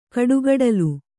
♪ kaḍugaḍalu